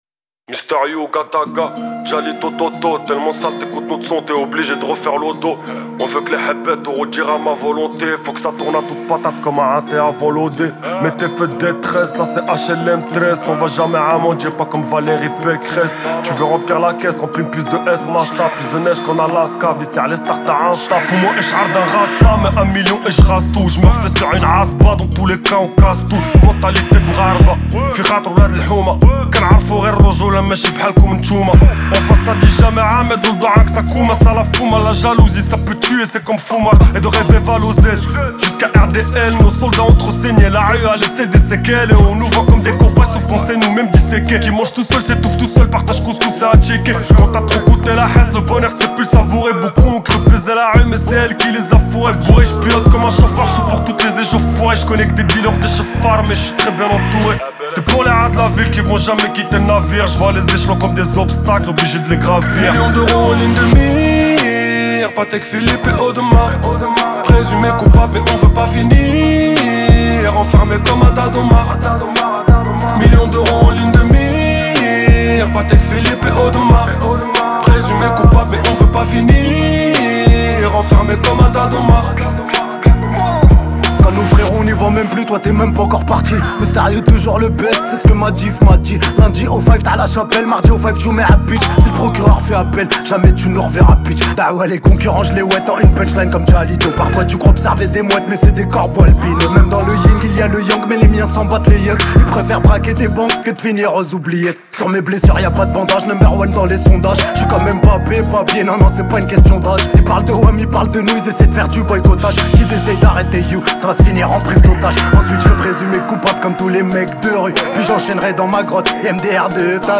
french rap, pop urbaine Télécharger